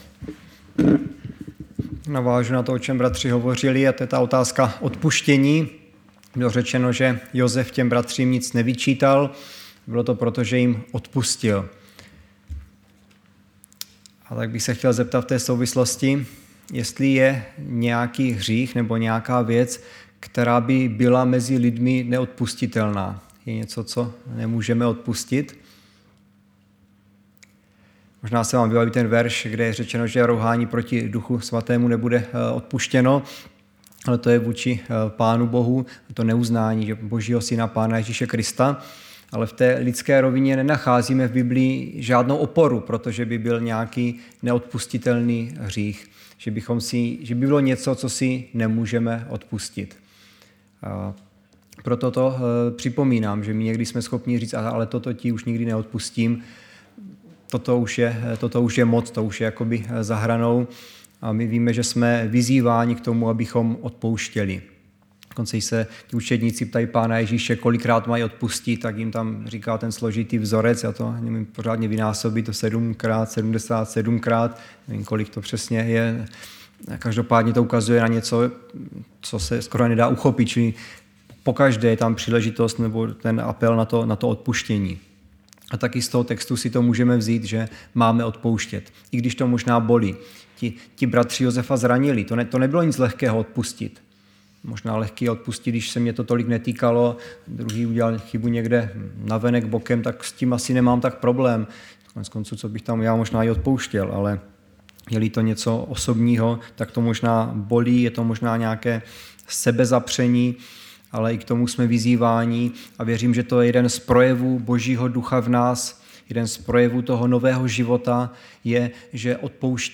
Středeční vyučování
Záznamy z bohoslužeb